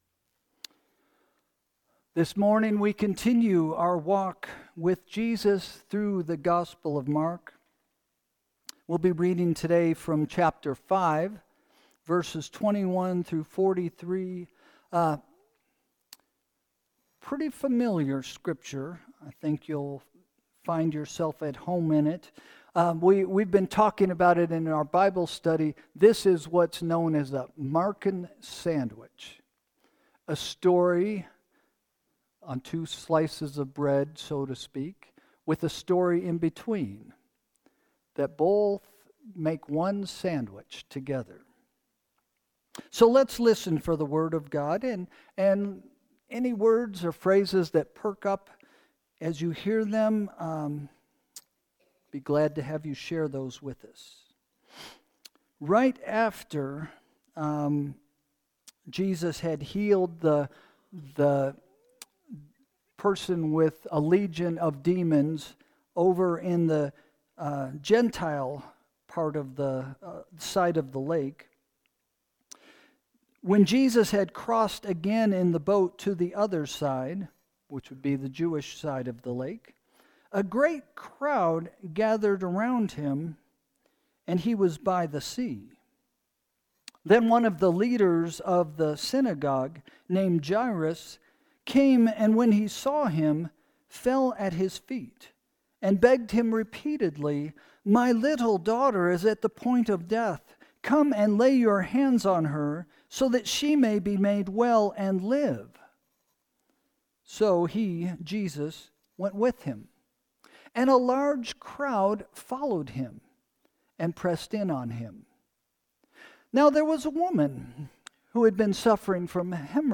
Sermon – November 16, 2025 – “Follow” – First Christian Church